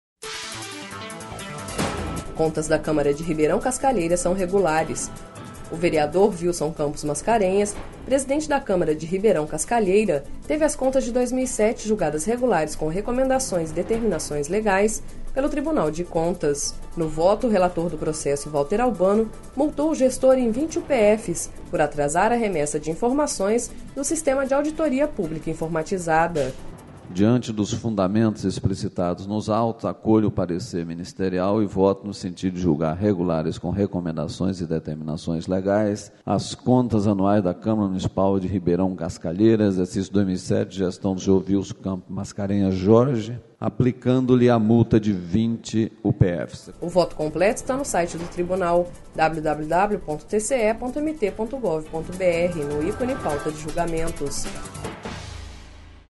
Sonora: Valter Albano– conselheiro do TCE-MT